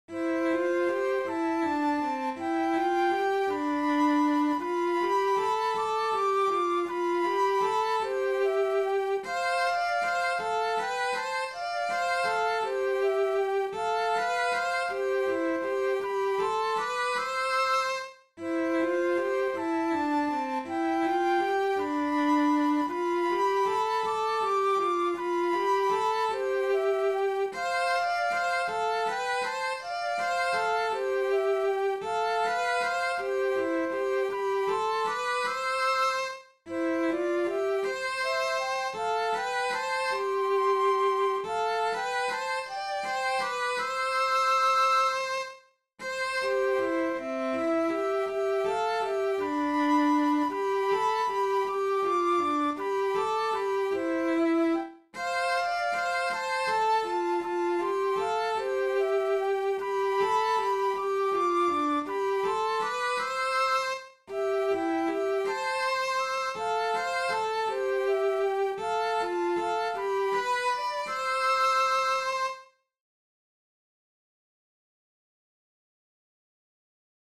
Paaskysen-viserrys-sello-ja-huilu.mp3